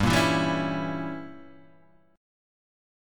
Gdim7 chord